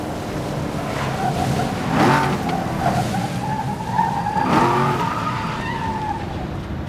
Index of /server/sound/vehicles/lwcars/eldorado
slowing.wav